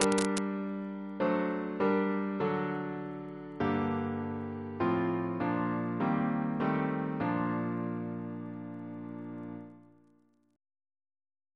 Single chant in B♭ Composer: C. Hylton Stewart (1884-1932), Organist of Rochester and Chester Cathedrals, and St. George's, Windsor Reference psalters: ACB: 79; ACP: 21